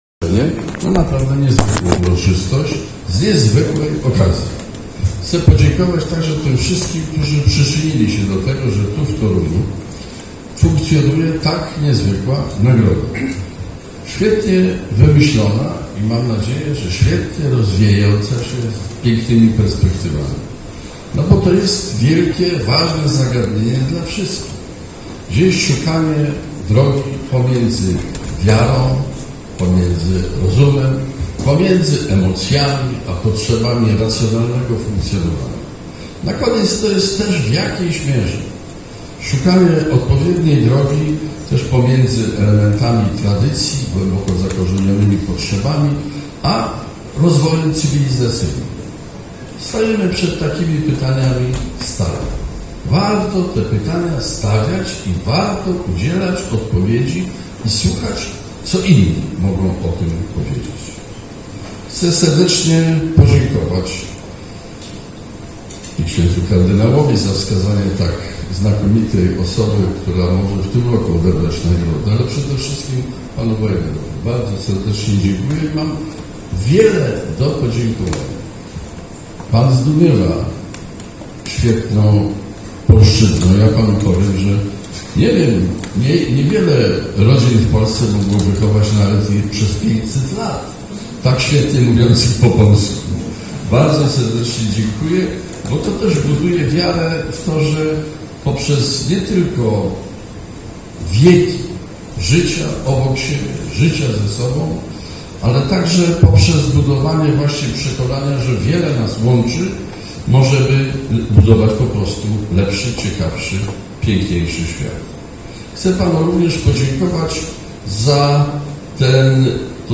To wyjątkowa nagroda i ważne zagadnienie dla nas wszystkich, którzy szukamy obecnie drogi między wiarą a rozumem, emocjami a potrzebą racjonalnego funkcjonowania, a także głęboko zakorzenioną tradycją a rozwojem cywilizacyjnym. Jestem przekonany, że to wyróżnienie ma przed sobą wspaniałą przyszłość – mówił prezydent Bronisław Komorowski.
Wystąpienie audio:
prezydent_bronislaw_komorowski.mp3